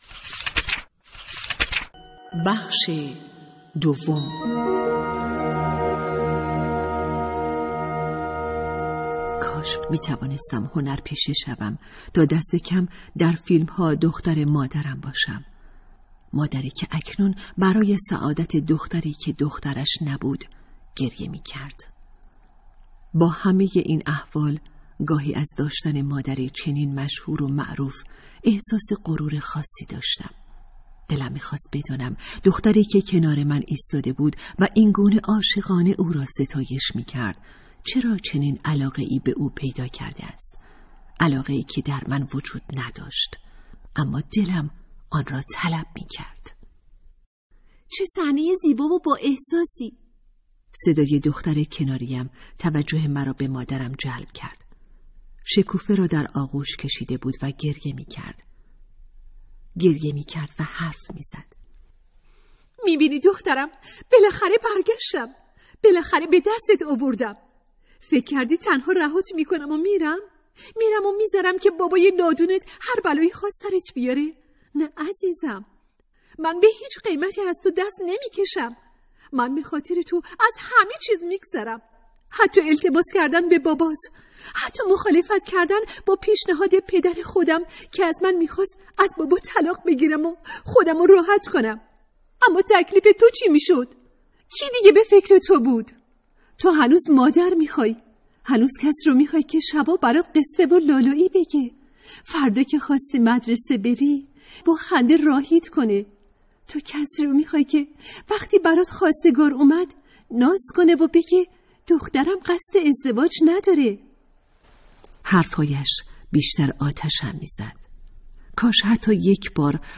کتاب صوتی | دختران آفتاب (02)